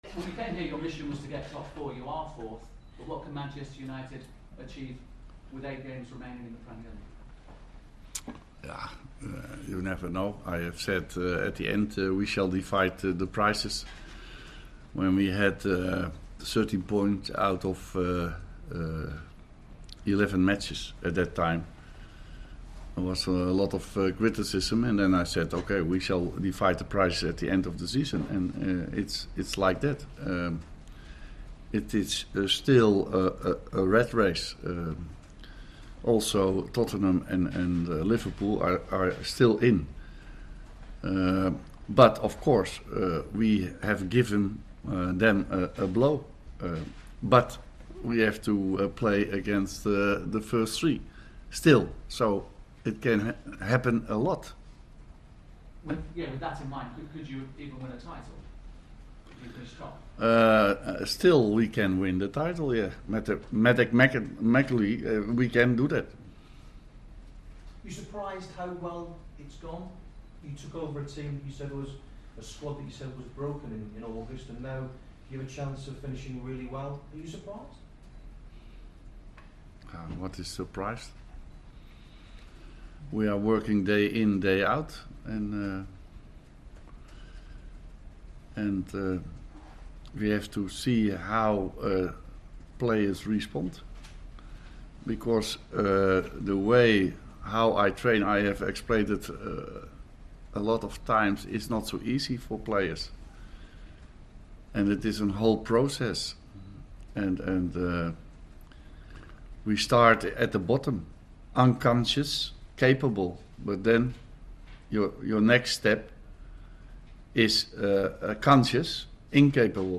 United manager holds press conference before Reds take on Villa